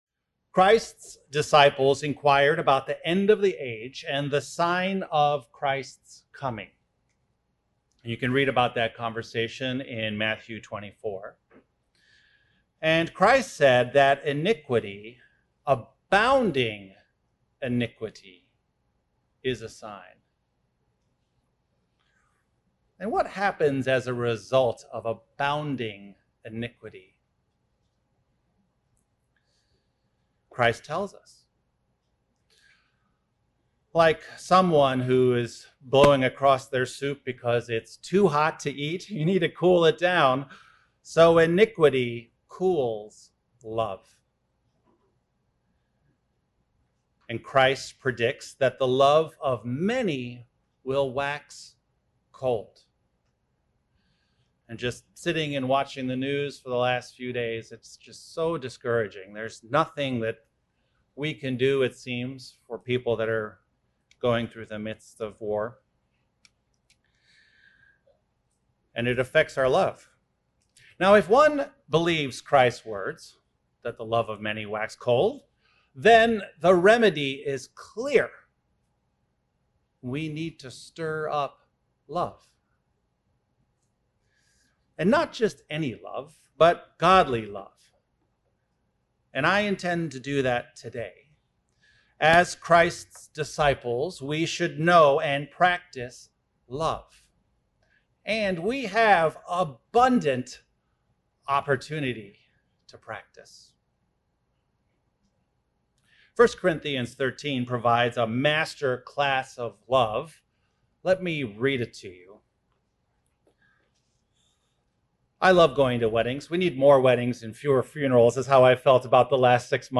Sermons
Given in Beloit, WI